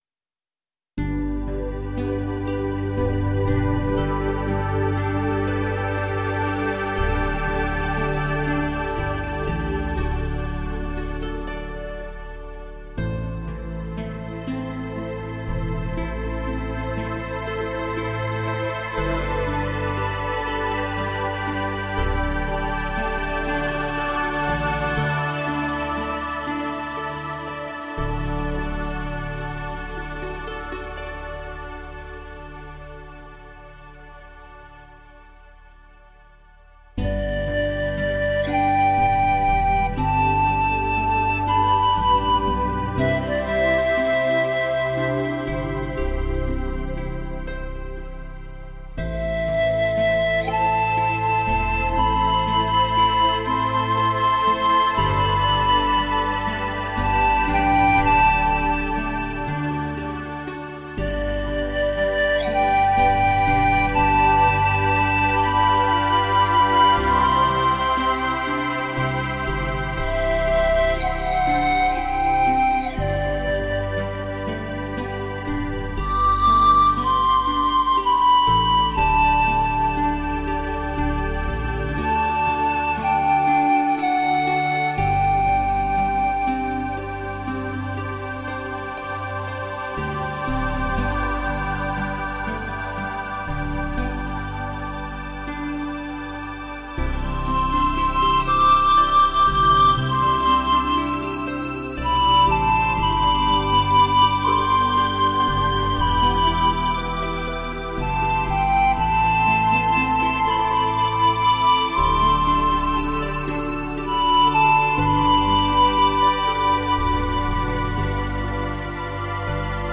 极具柔和动听，优美醉人的弦律，和绝妙的音色由如泡沫般细腻、
薄纱般绵密的声响所纺织而成，在空气中漫漫弥散让你沉静其中……
和声、新颖的配器、丰富的音色、浓郁的浪漫情调给人以全新的感受，形成了特有的美感。